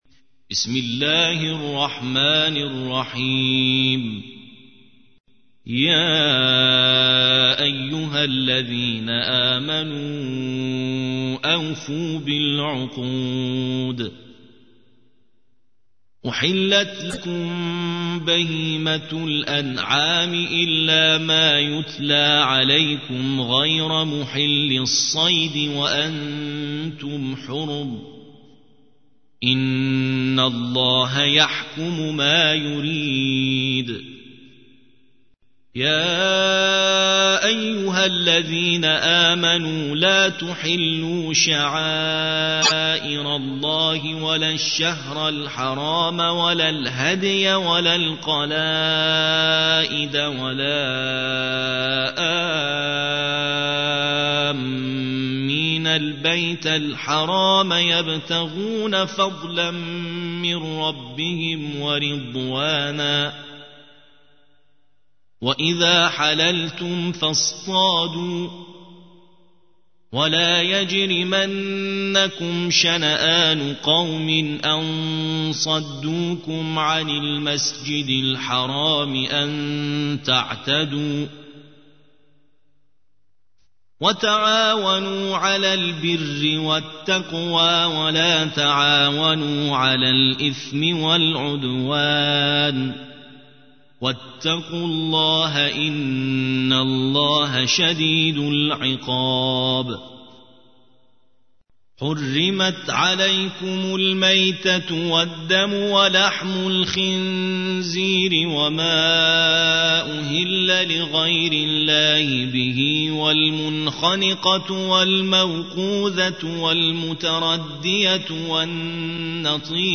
5. سورة المائدة / القارئ